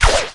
crow_dryfire_01.ogg